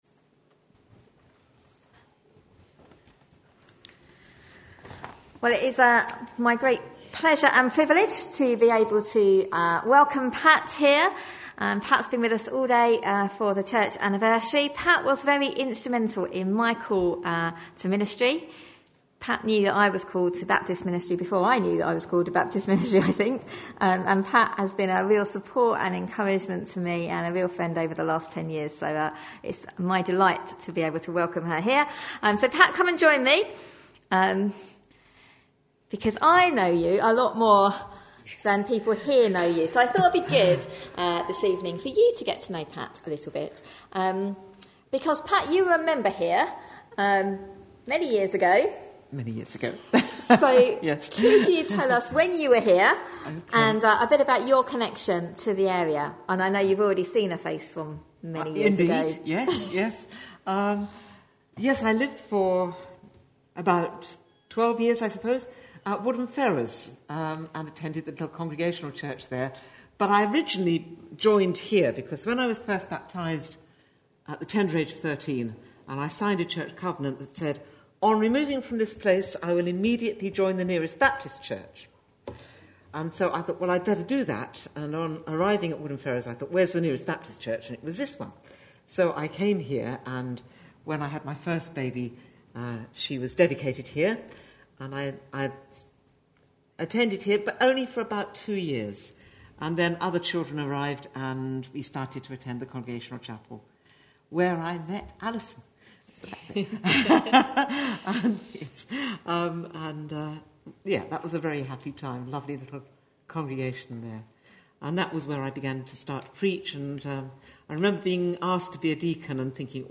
A sermon preached on 11th May, 2014, as part of our 109th Church Anniversary series.